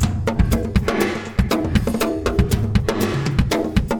Pensive (Drums) 120BPM.wav